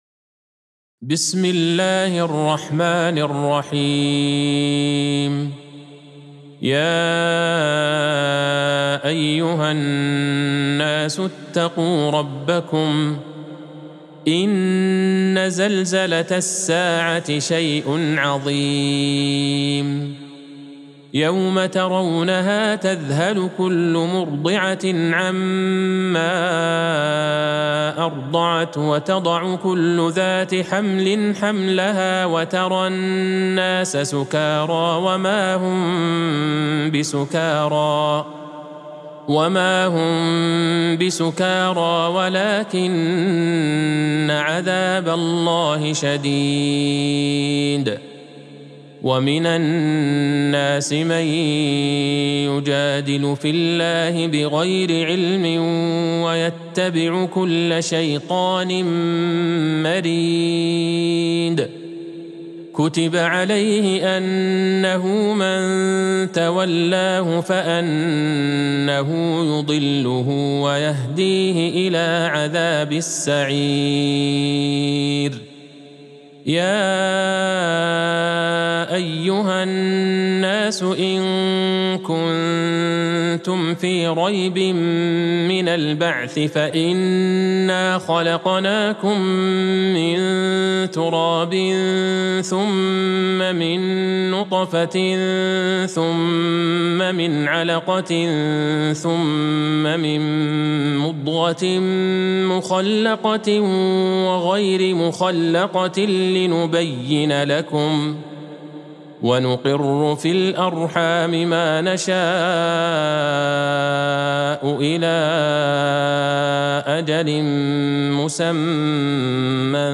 سورة الحج Surat Al-Hajj | مصحف المقارئ القرآنية > الختمة المرتلة ( مصحف المقارئ القرآنية) للشيخ عبدالله البعيجان > المصحف - تلاوات الحرمين